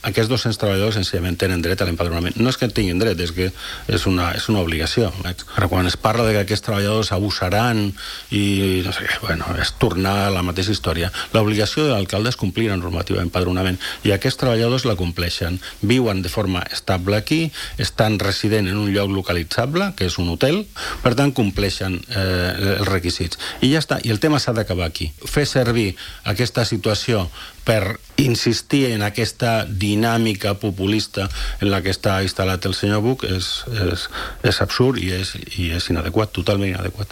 El portaveu de Calella en Comú Podem, Sebastián Tejada, ha passat aquest dimarts per l’entrevista del programa matinal A l’FM i+, on ha tornat a denunciar la negativa de l’Ajuntament a empadronar els 200 obrers que treballen en les obres del Camp Nou i que estan allotjats en hotels de Calella.